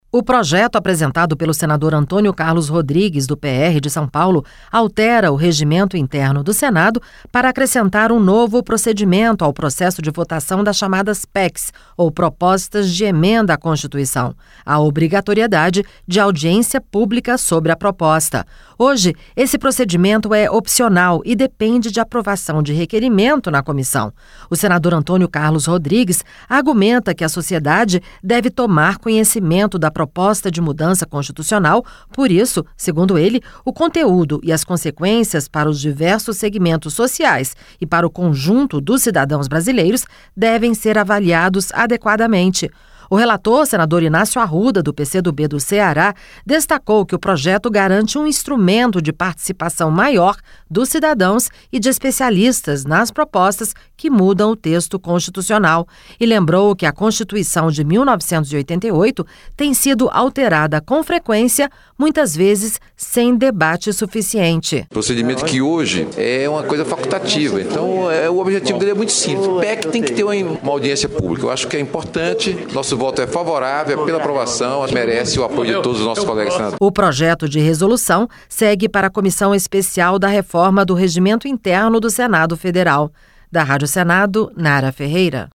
Rádio Senado
O relator, senador Inácio Arruda, do PCdoB do Ceará, destacou que o projeto garante um instrumento de participação maior dos cidadãos e de especialistas nas propostas que mudam o texto constitucional.